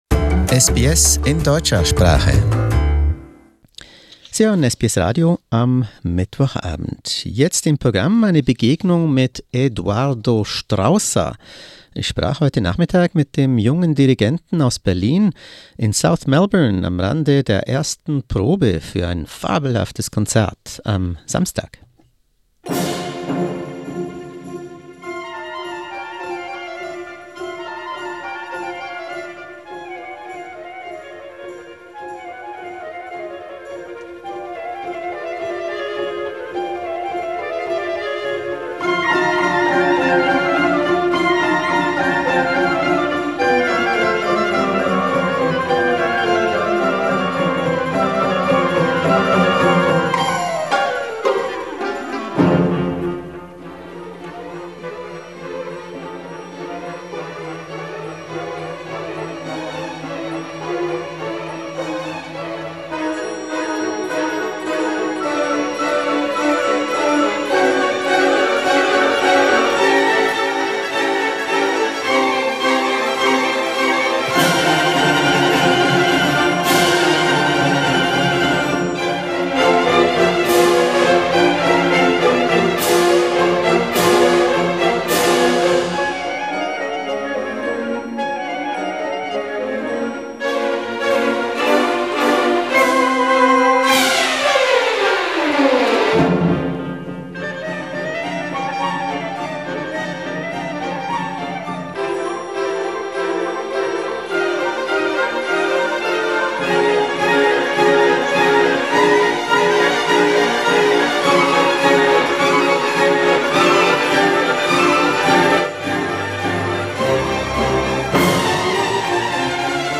Interviewtermin in South Melbourne